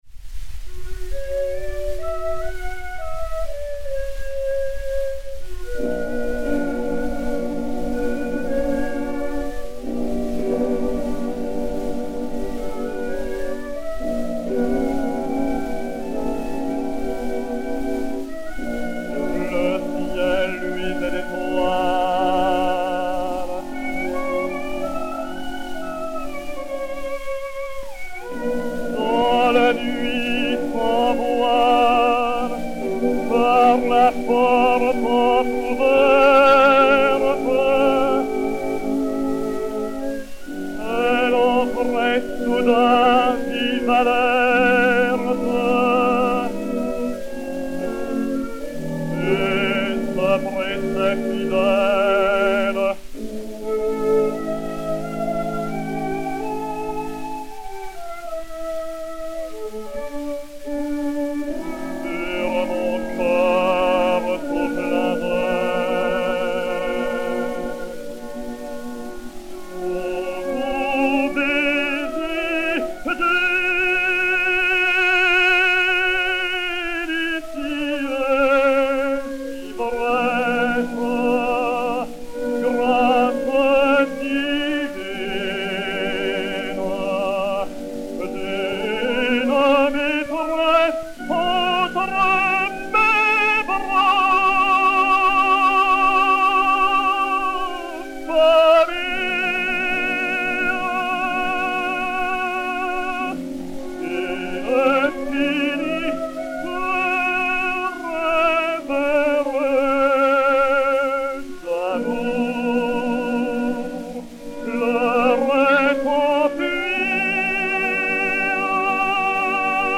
et Orchestre
XP 4535, enr. à Paris fin 1909